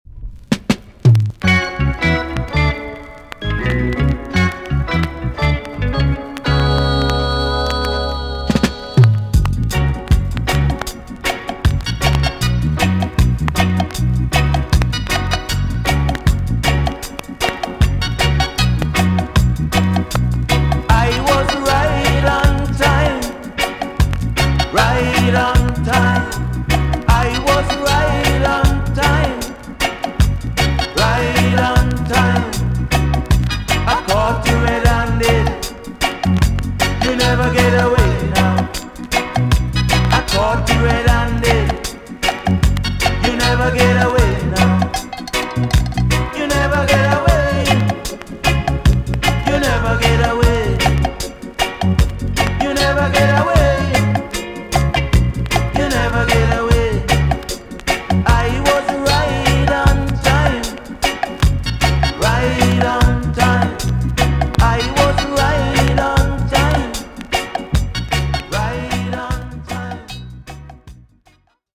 TOP >REGGAE & ROOTS
VG+ 軽いチリノイズが入ります。
NICE VOCAL TUNE!!